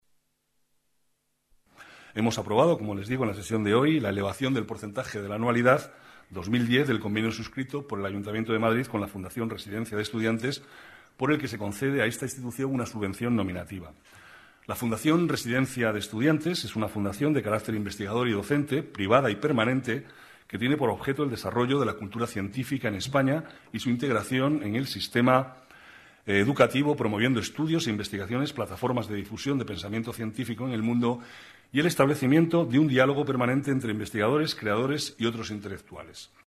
Nueva ventana:Declaraciones vicealcalde, Manuel Cobo: Junta Gobierno, subvención Residencia Estudiantes